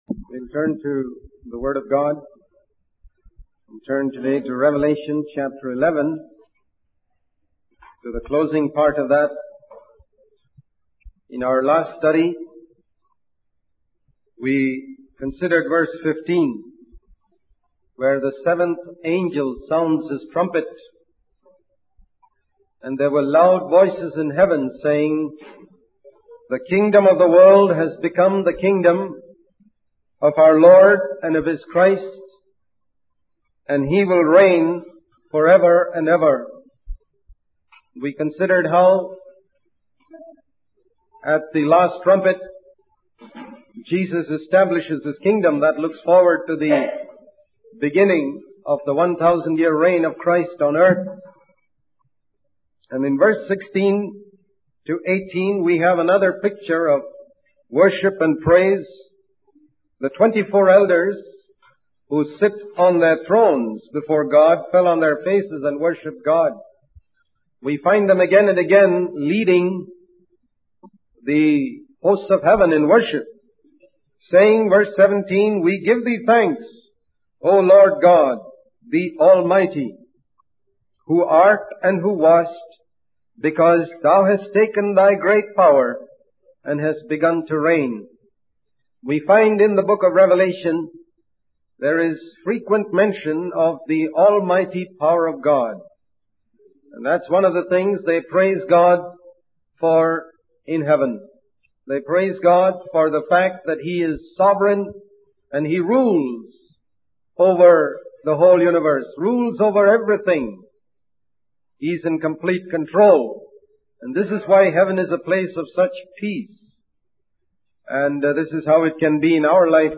In this sermon, the preacher emphasizes the importance of avoiding pride and rebellion, as these traits make people like Satan. He highlights that Satan infects people with pride and rebellion to make them like himself.